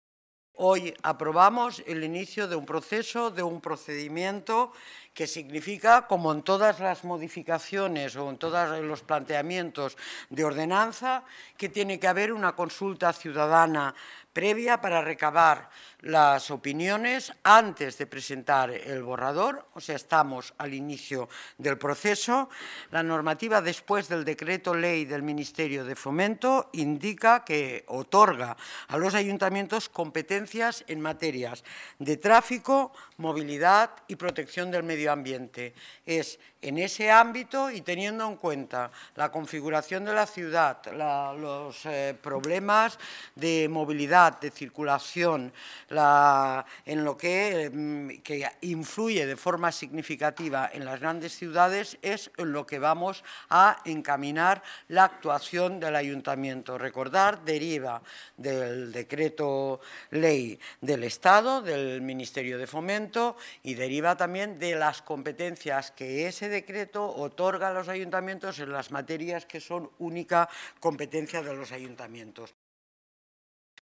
Inés Sabanés y Rita Maestre durante la rueda de prensa
Inés Sabanés. Delegada de Medio Ambiente y Movilidad